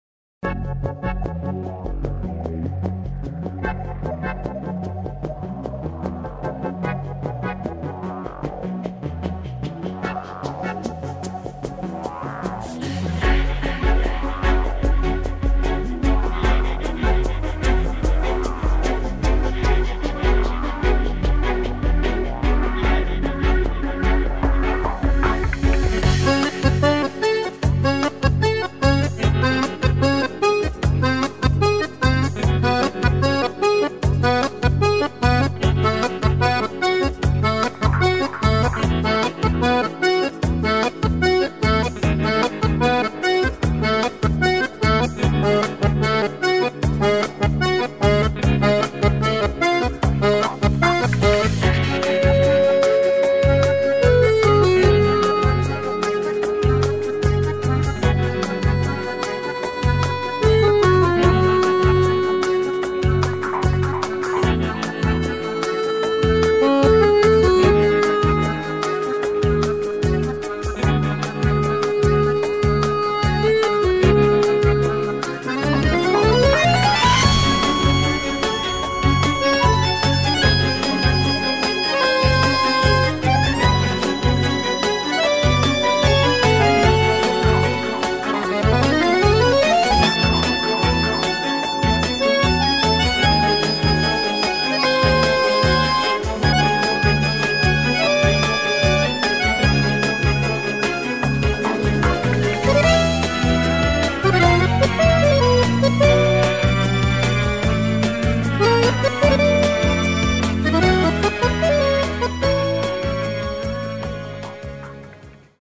Специальность: Баянист.